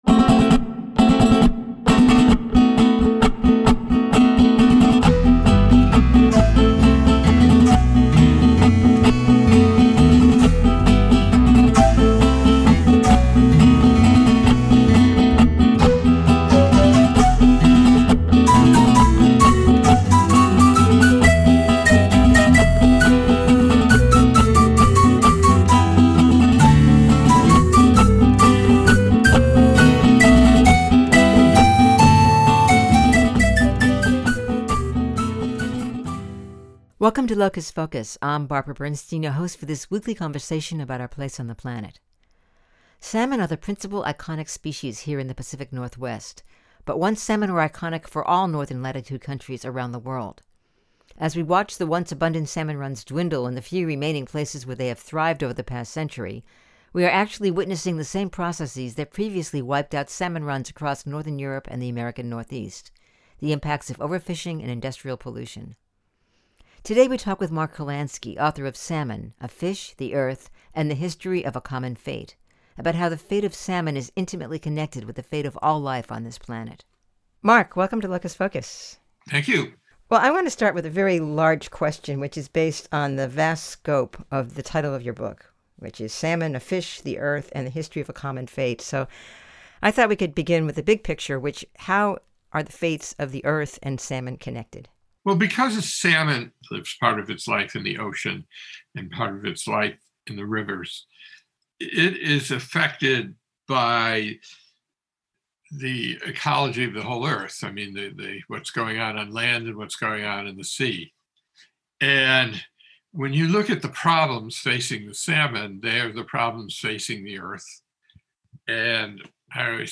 On this episode of Locus Focus, we talk with Mark Kurlansky, author Salmon: A Fish, the Earth, and the History of a Common Fate, about how the fate of salmon is intimately connected with the fate of all life on this planet.